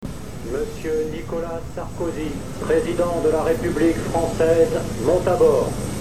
MP3 / Annonce du  Président à bord